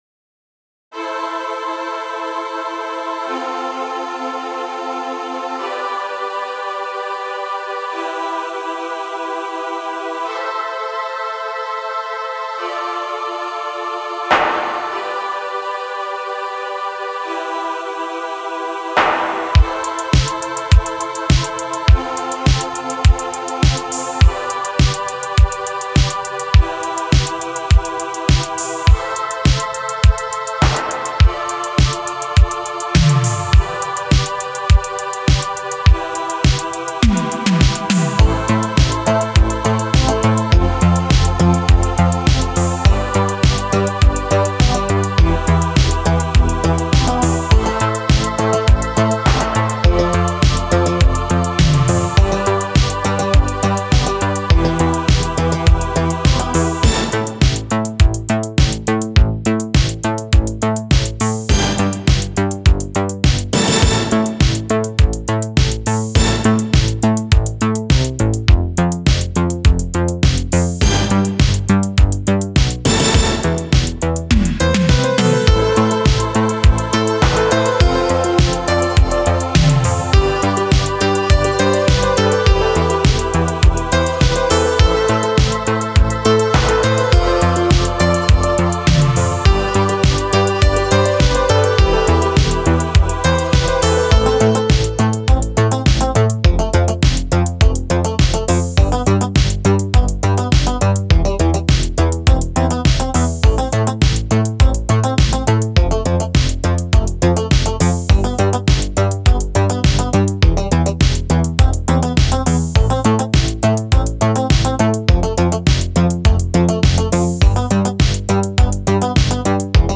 В 2013 появилась итало версия